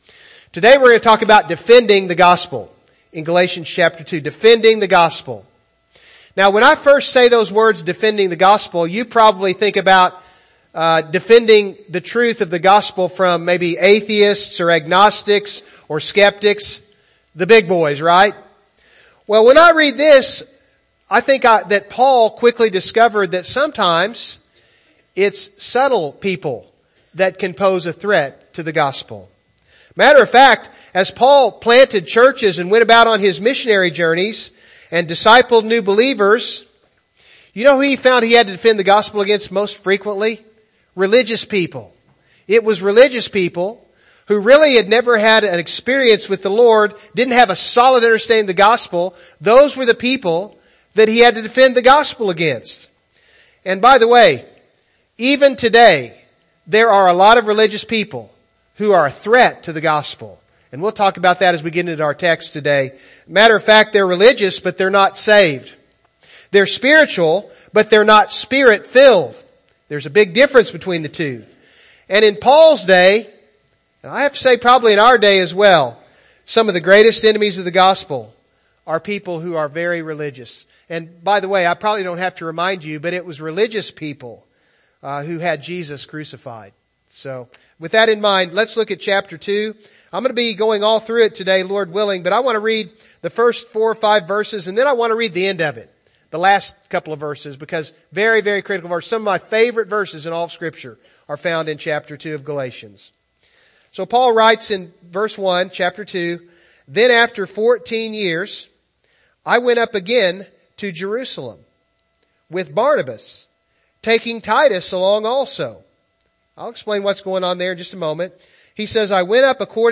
Passage: Galatians 2 Service Type: Morning Service